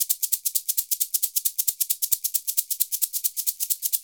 Index of /90_sSampleCDs/Univers Sons - Basicussions/11-SHAKER133